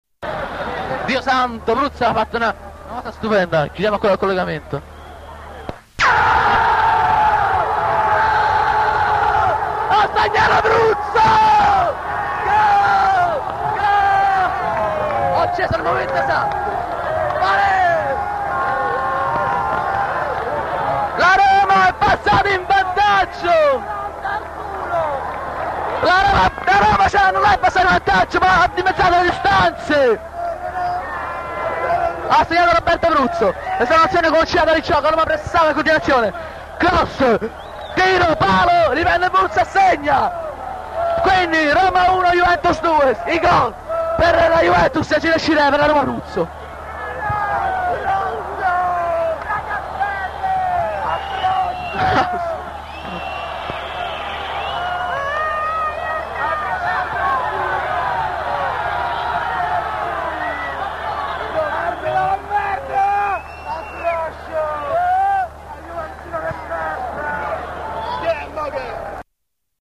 "Ti invio alcuni pezzi della cassetta che ho registrato il 13/04/1980 all'Olimpico durante Roma-Juventus 1-3.
Comunque nel 5° mp3 segna Pruzzo e successivamente si scatena una rissa in curva sud contro uno juventino che evidentemente non aveva esultato, ecco perchè la gente urla 'Juventino di m***a!'